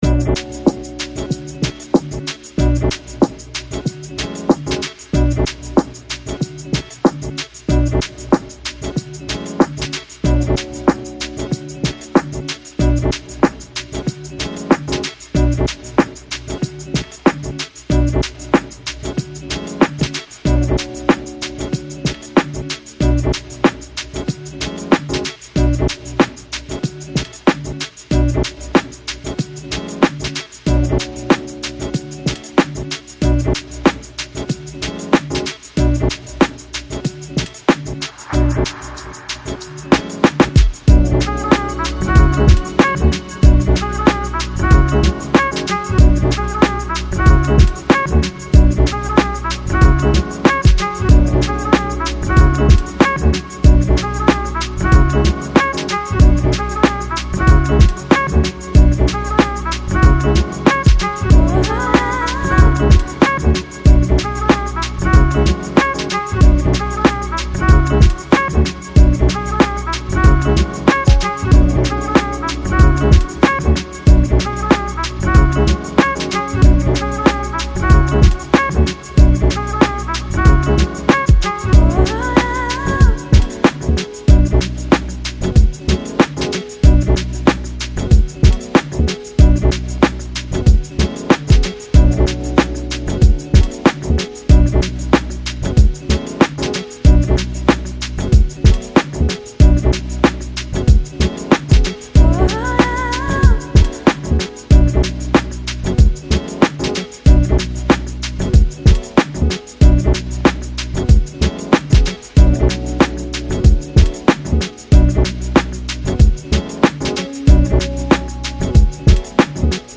Chill Out, Lounge, Downtempo Год издания